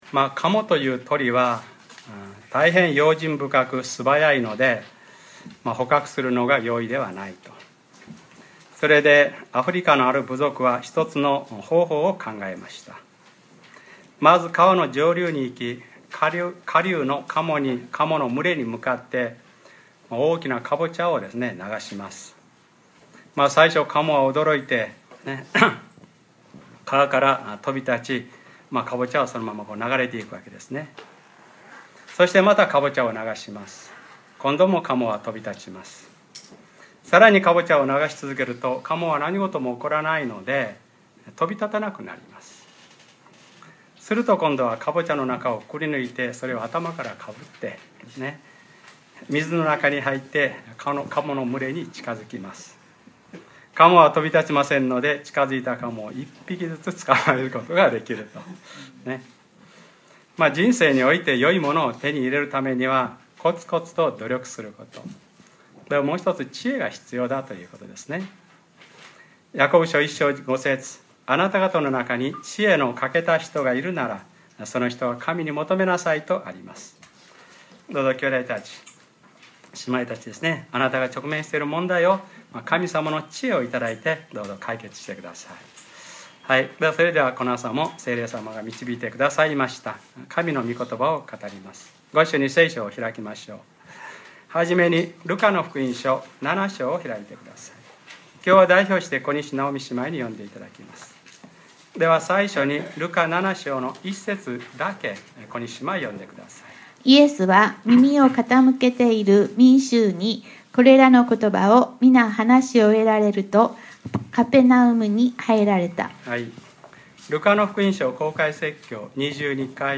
2013年7月14日(日）礼拝説教 『ルカｰ１２ ただおことばをいただかせてください』